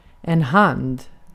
Ääntäminen
Synonyymit giv näve nypa ånd karda Ääntäminen : IPA: [ɛn hand] Tuntematon aksentti: IPA: /hand/ Haettu sana löytyi näillä lähdekielillä: ruotsi Käännös Konteksti Substantiivit 1. käsi 2. korttikäsi korttipeli Artikkeli: en .